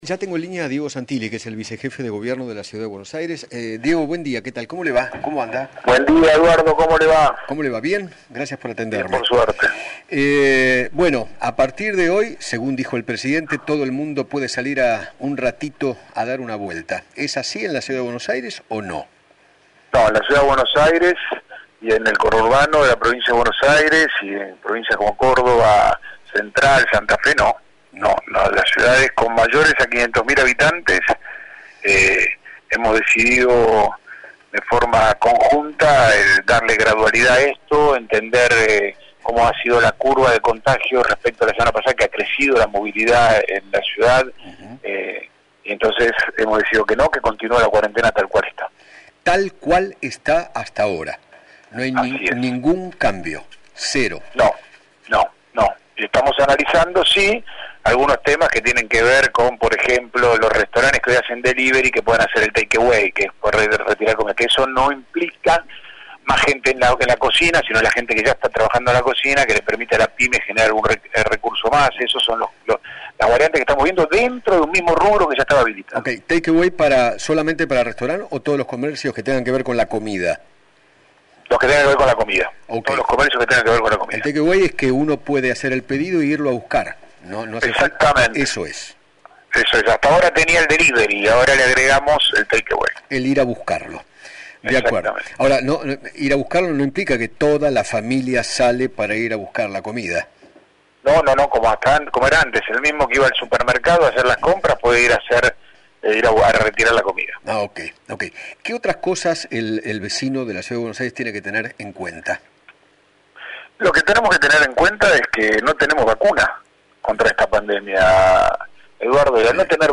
Diego Santilli, vice jefe de Gobierno de la Ciudad de Buenos Aires, dialogó con Eduardo Feinmann sobre la decisión de no flexibilizar la cuarentena en CABA, Buenos Aires y otras provincias del país. Además, contó las variantes que se están analizando.